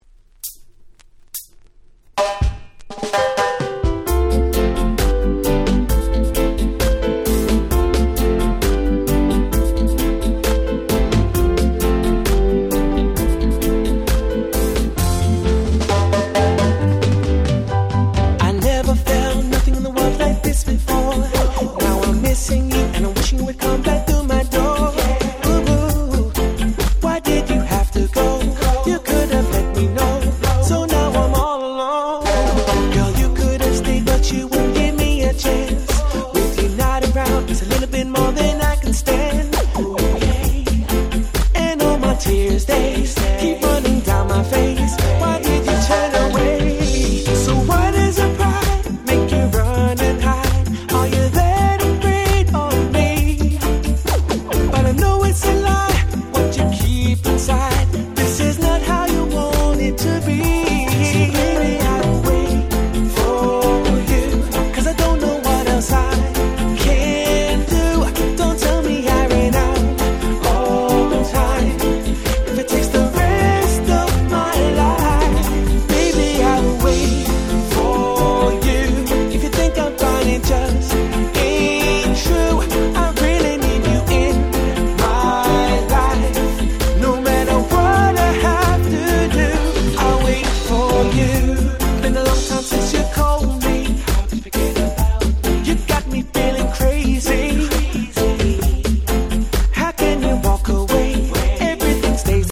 08' Super Nice Cover Reggae !!